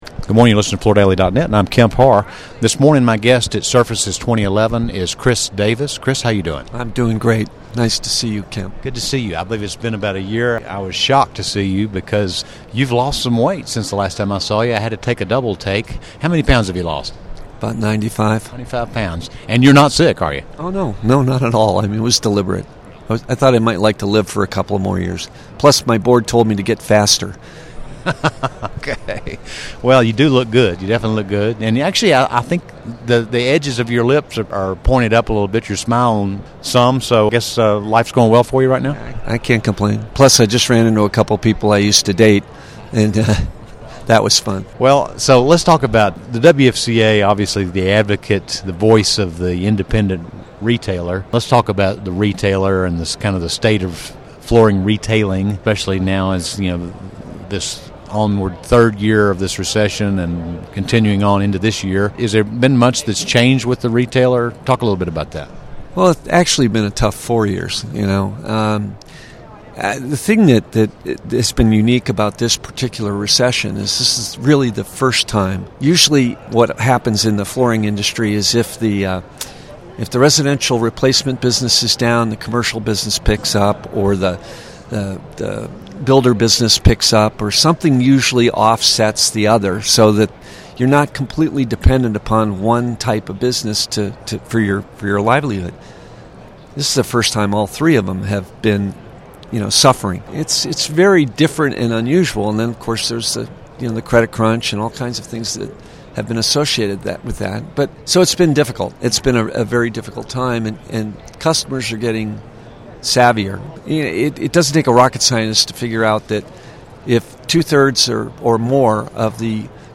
Interviews with all segments of the flooring industry including; suppliers, manufacturers distributors & retail flooring stores.